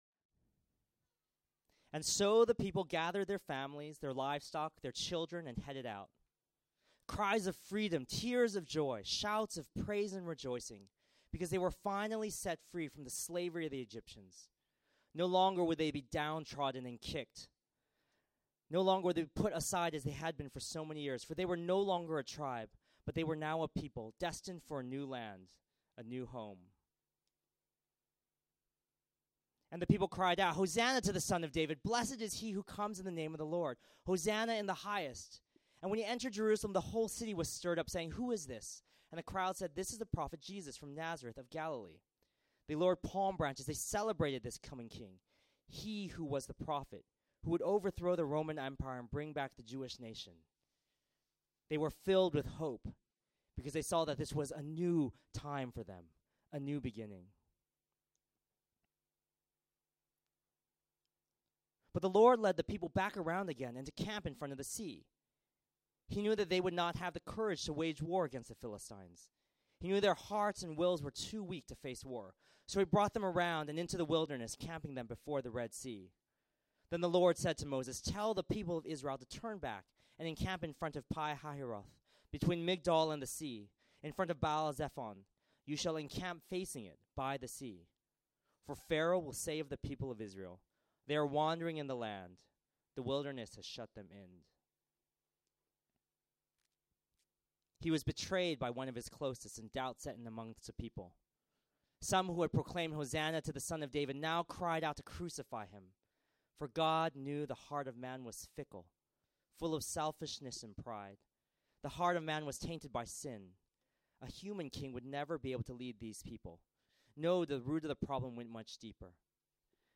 Category Sermon Audio